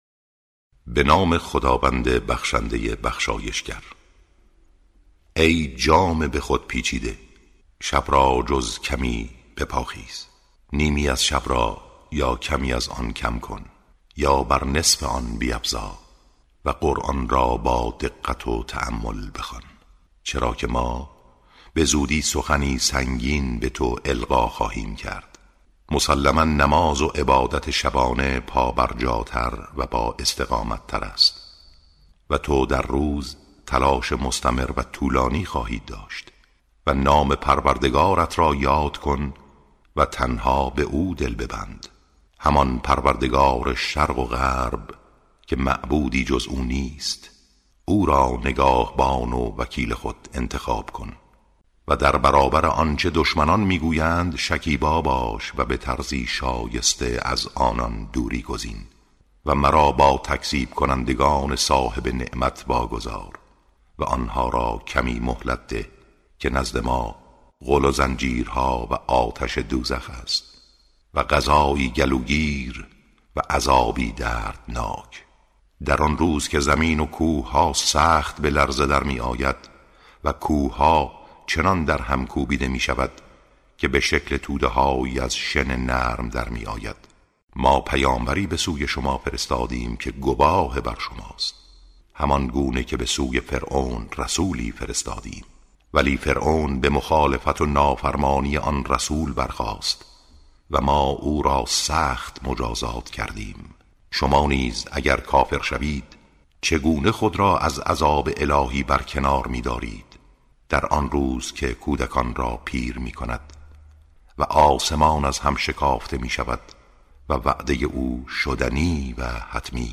ترتیل سوره(مزمل)